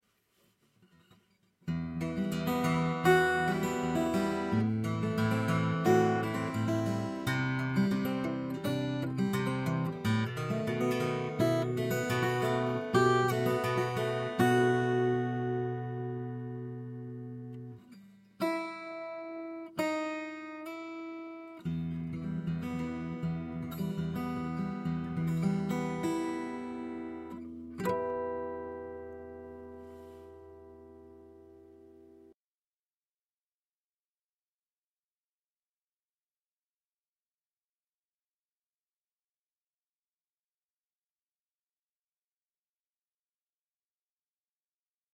自分の作ったギターです。
今度は、DAWで録音してみます。iRigとＰＣのみです。
iRig_Natural.mp3